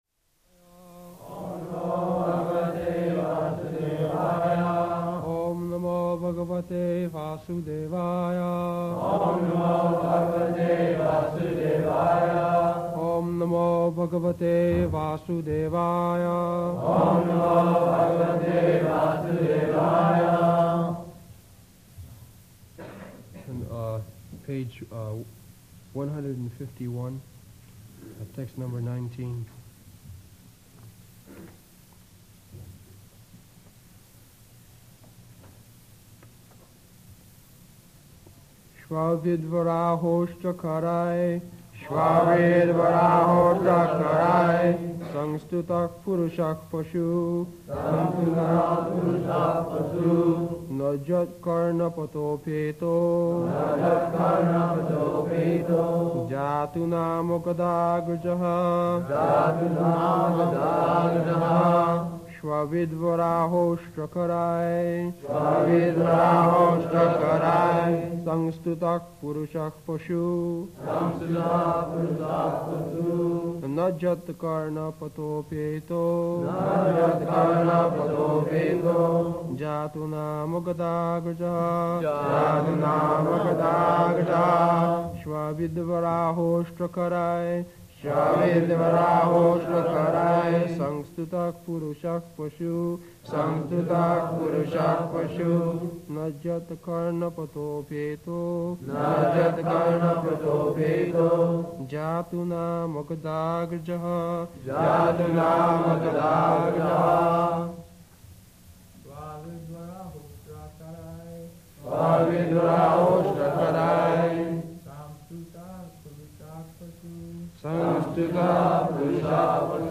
-- Type: Srimad-Bhagavatam Dated: June 14th 1972 Location: Los Angeles Audio file
[leads chanting of verse] [Prabhupāda and devotees repeat] śva-viḍ-varāhoṣṭra-kharaiḥ saṁstutaḥ puruṣaḥ paśuḥ na yat-karṇa-pathopeto jātu nāma gadāgrajaḥ [ SB 2.3.19 ] Prabhupāda: [corrects devotees' pronunciation] That's all.